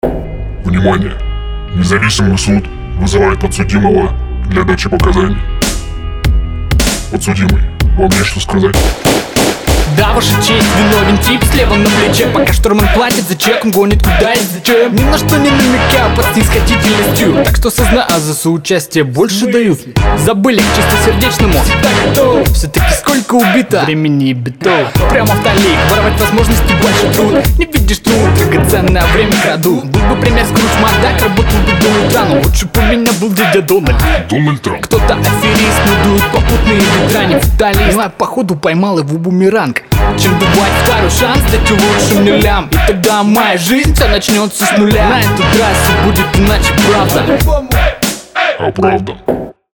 Идейное построение отдалённо слышится, это хорошо и ценно, но чересчур уж дёргано вышло, с постоянными выключениями бита, и очень плохо воспринимается.